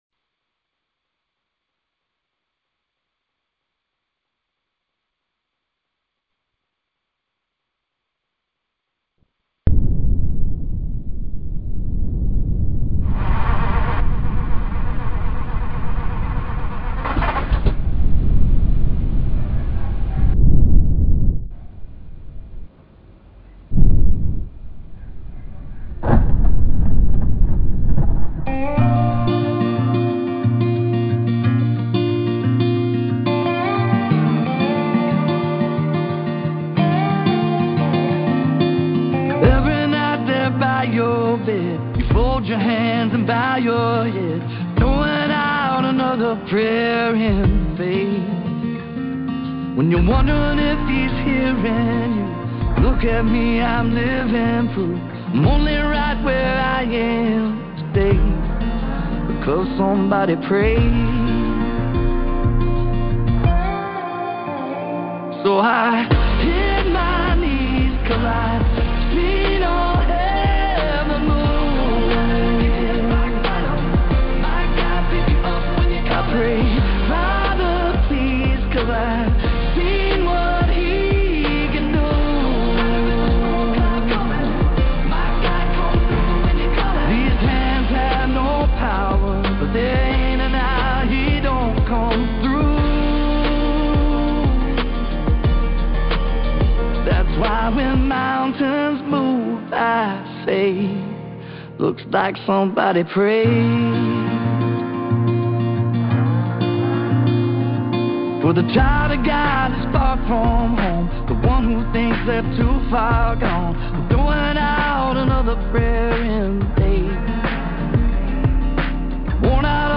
Details Series: Conference Call Fellowship Date: Thursday, 06 March 2025 Hits: 319 Scripture: Psalm 119:34 Play the sermon Download Audio ( 5.94 MB )